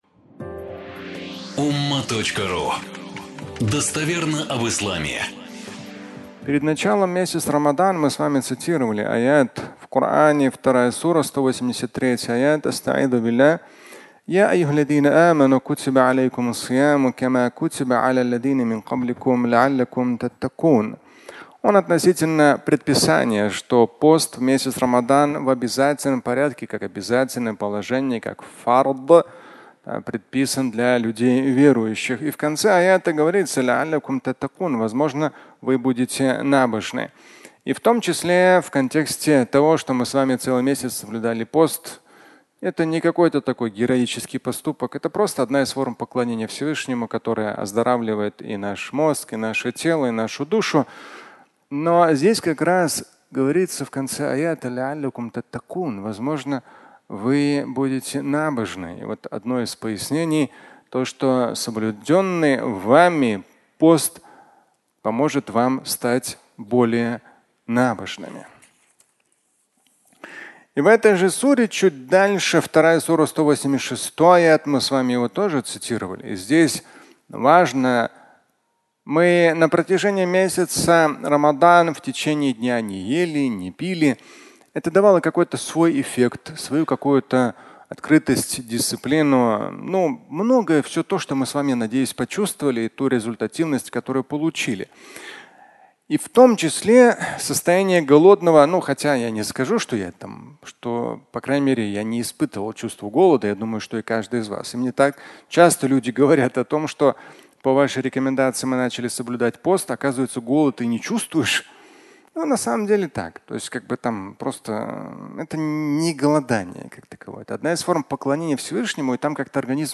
Фрагмент пятничной проповеди